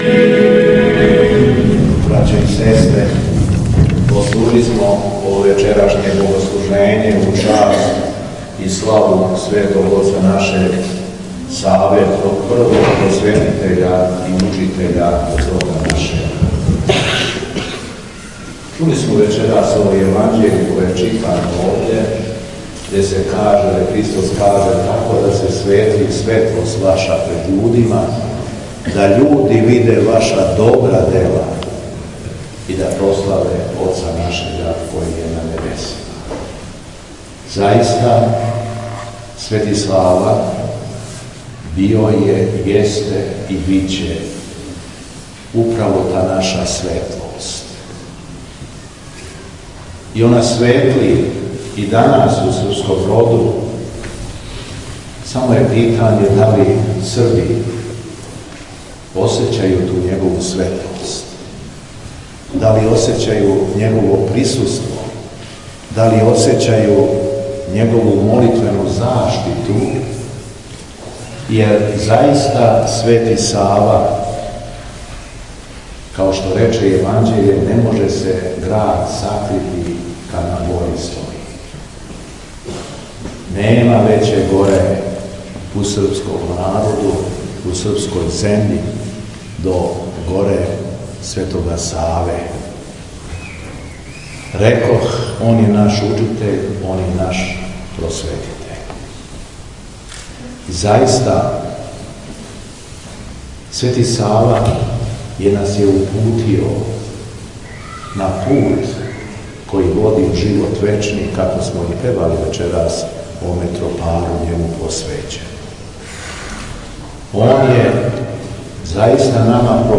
Беседа Његовог Преосвештенства Епископа шумадијског г. Јована
Преосвећени владика је у цркви препуној дечице надахнуто беседио:
Уочи Савиндана, 13/26. јануара 2024. године, у јагодинској цркви Покрова Пресвете Богородице у поподневним часовима Његово преосвештенство Епископ шумадијски Господин Јован служио је празнично бденије.